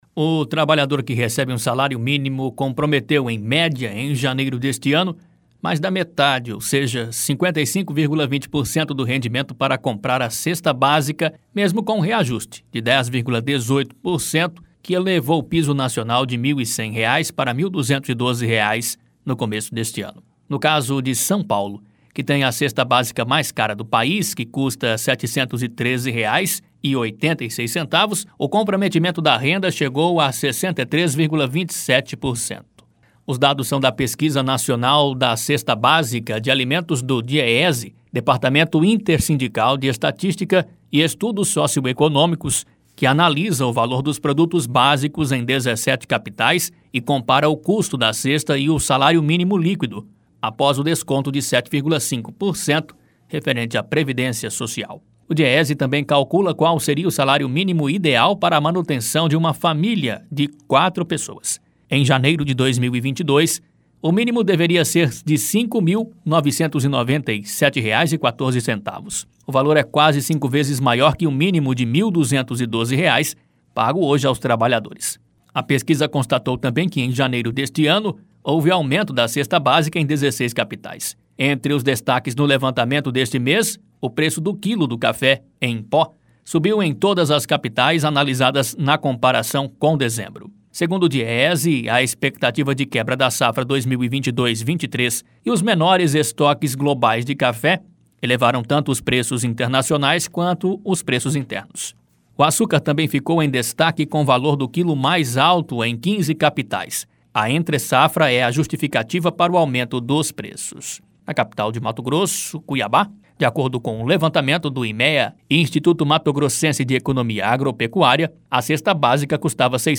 Boletins de MT 09 fev, 2022